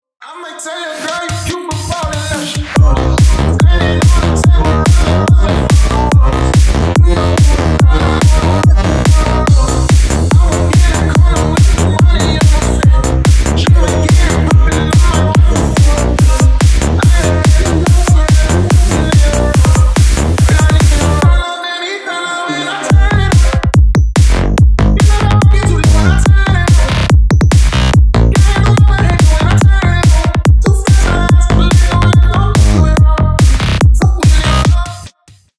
Ремикс
клубные # Psy Trance
ритмичные